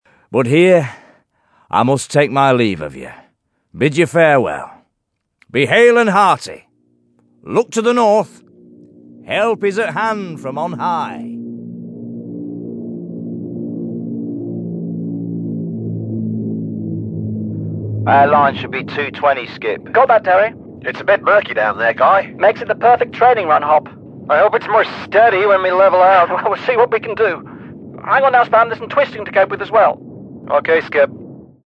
Discover a long and varied history in the Derwent and Hope Valleys. You will hear dramatic re-enactments from the past as your ghostly guides take you on this linear walk from Hope to Bamford.